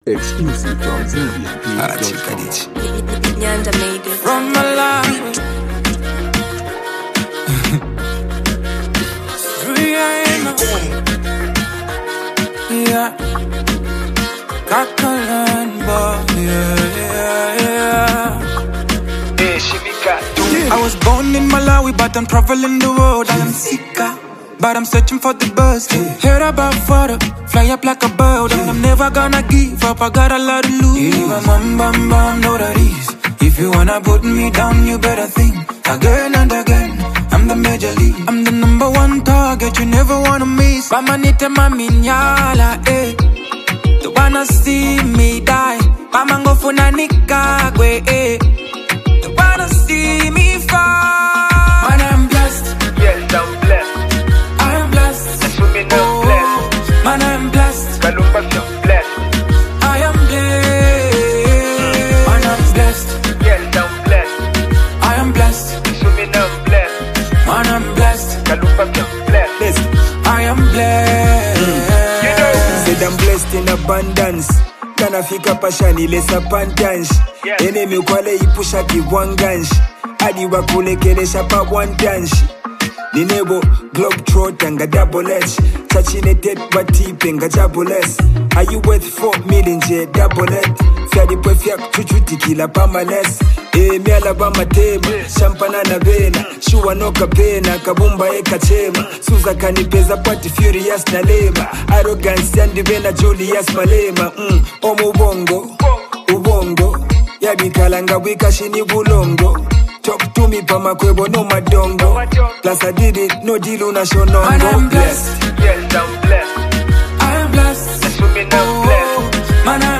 soulful and melodic style
sharp and thought-provoking rap delivery